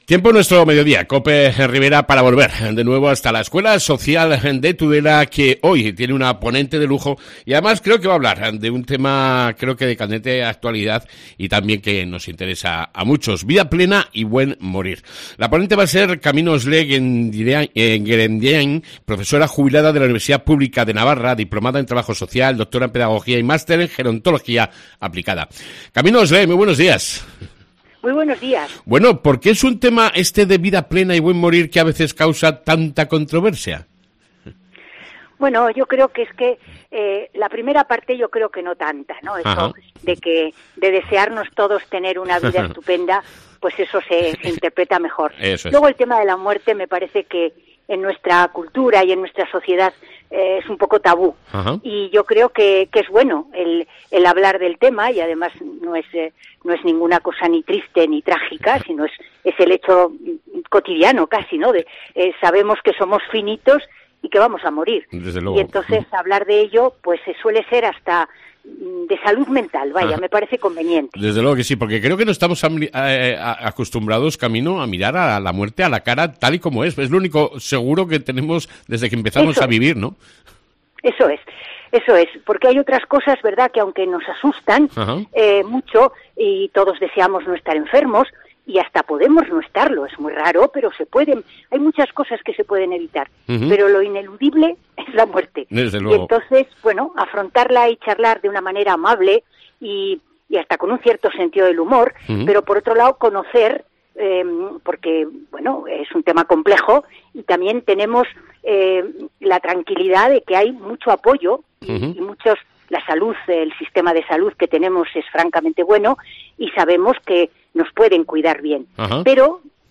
Hoy en Cope Ribera una nueva ponencia de la Escuela Social de Tudela.